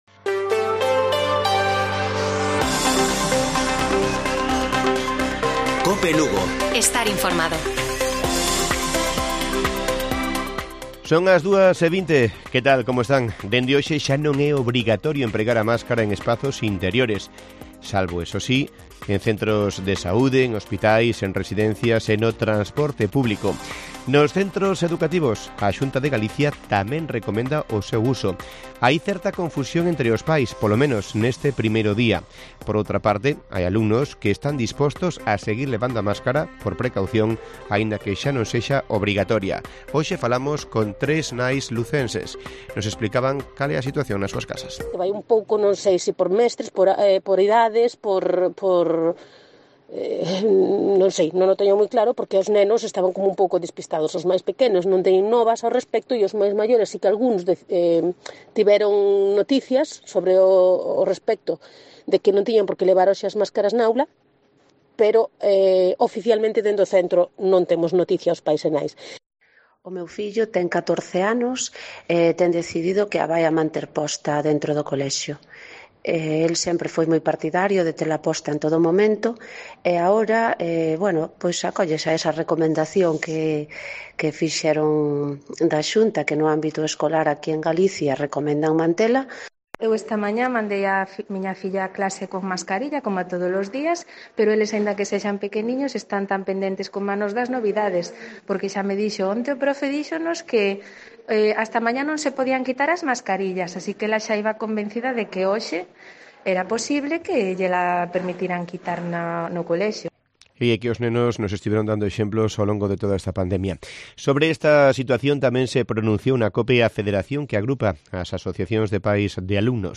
Informativo Mediodía de Cope Lugo. 20 de abril. 14:20 horas